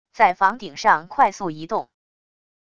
在房顶上快速移动wav音频